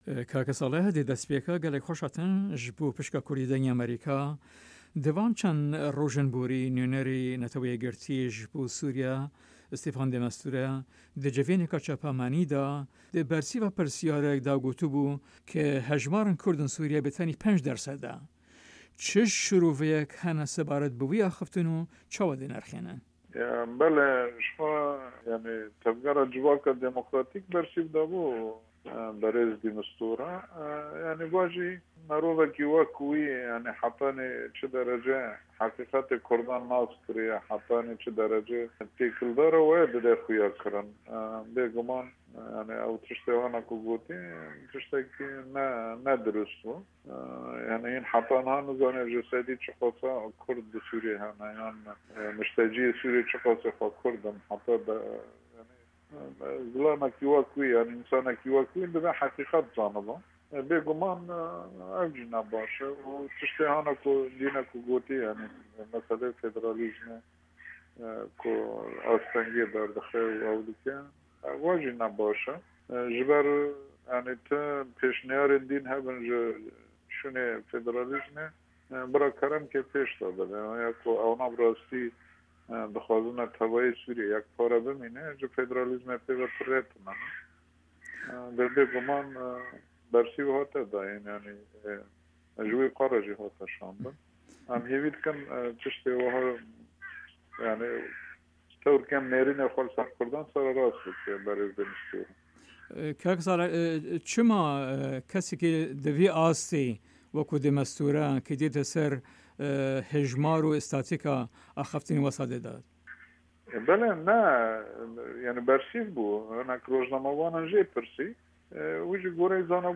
Interview with Salih Muslim